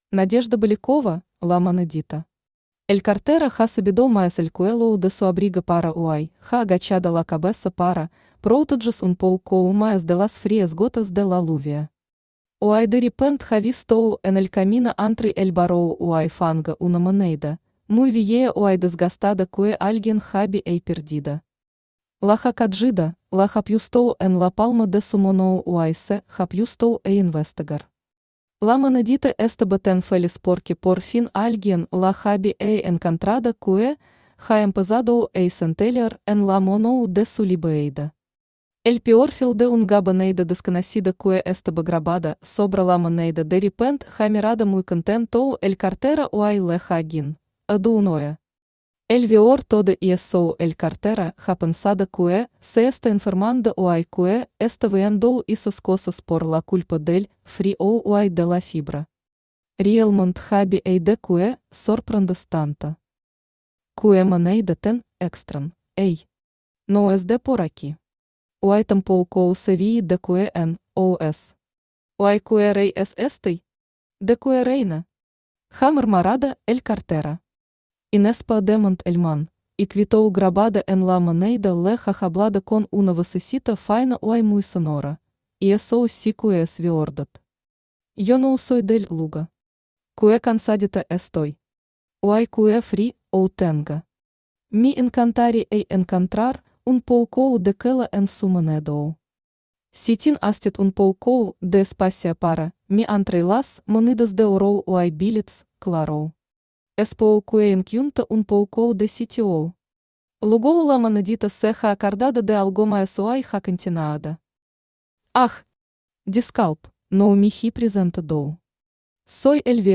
Aудиокнига La monedita Автор Надежда Александровна Белякова Читает аудиокнигу Авточтец ЛитРес.